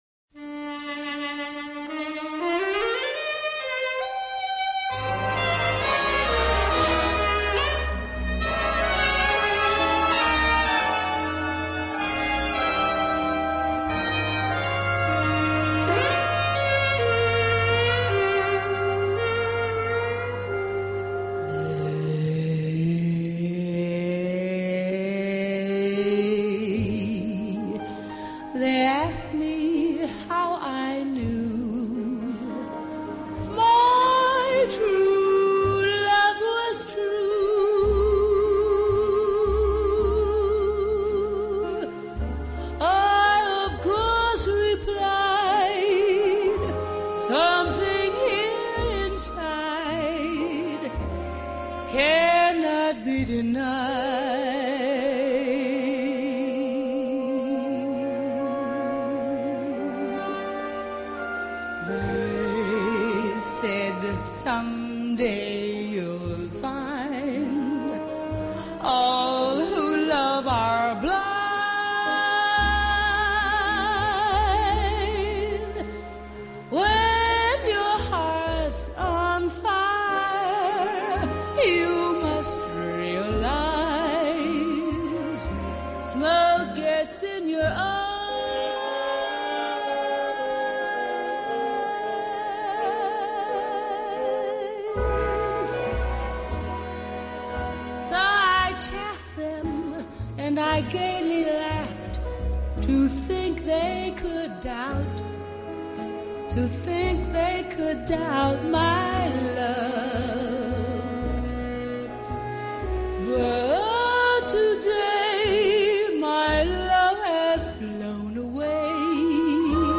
【爵士女伶】
精选爵士女声合辑
词意、编曲、配器、还有唱腔都很唯美，浪漫，也很感性。